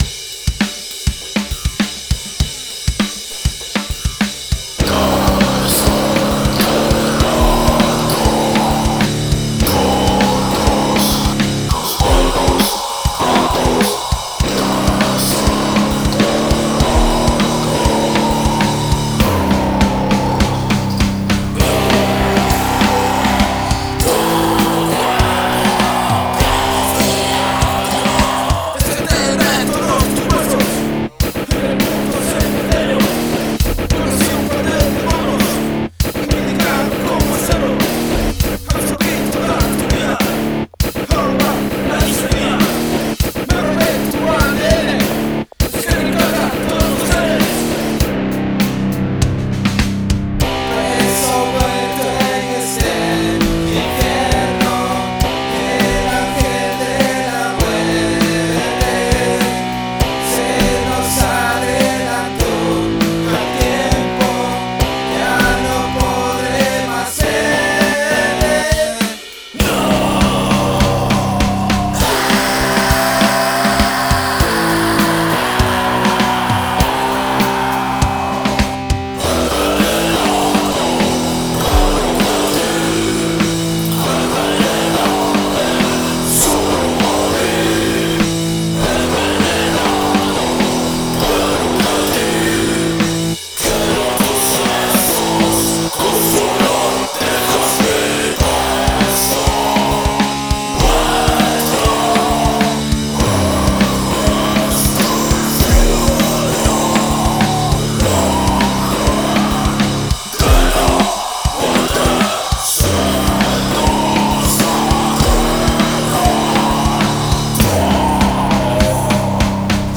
bajo
guitarra, voz
bateria, voz